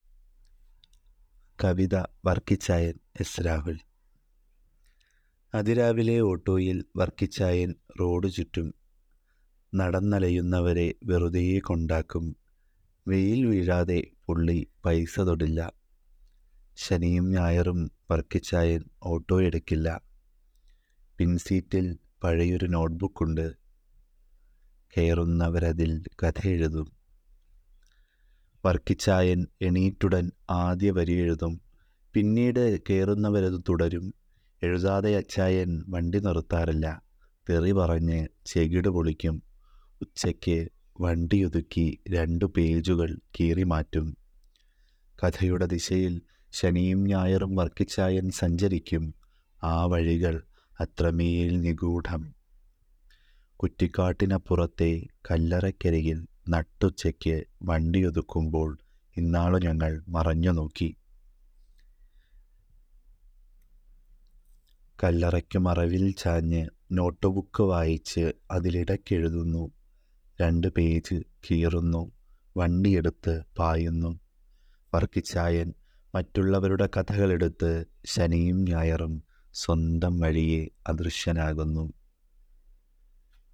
(കവിത)
വർക്കിച്ചായൻ-കവിത-audio-enhanced.wav